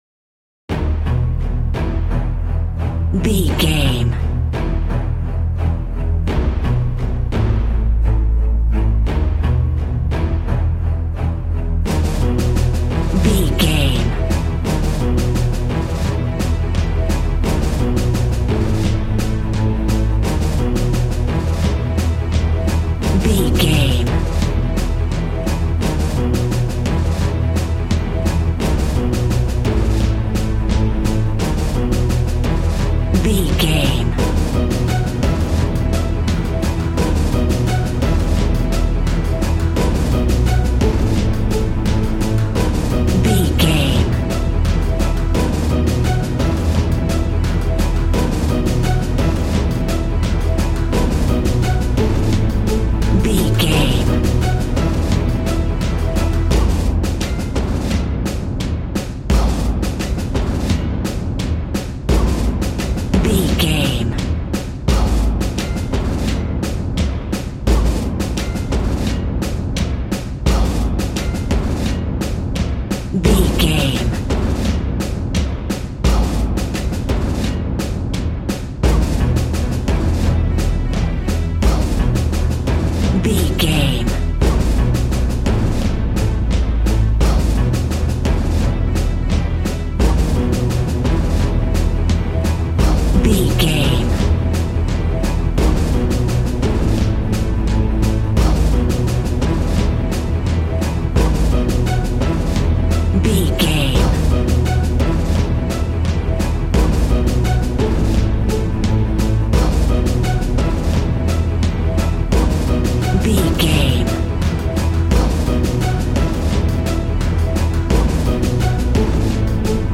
Epic / Action
Fast paced
In-crescendo
Uplifting
Ionian/Major
brass
synthesiser